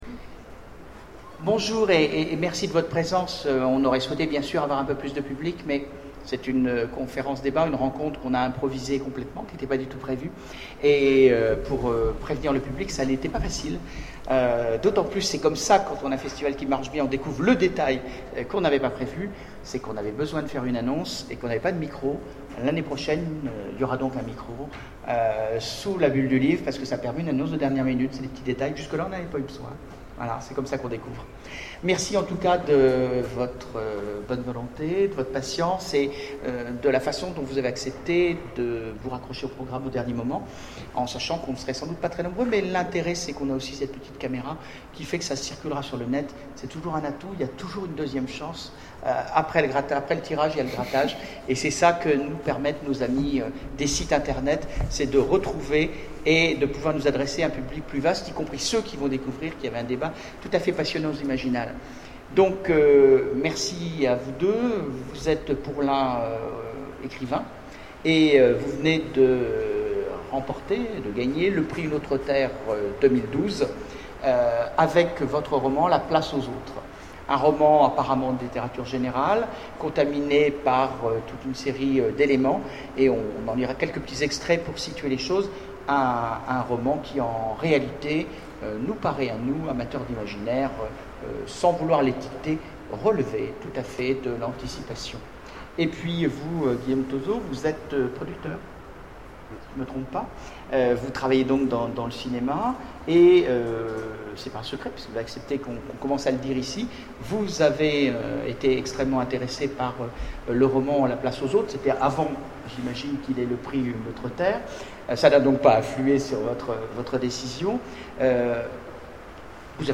Imaginales 2012 : Conférence Comment adapter un roman au cinéma ?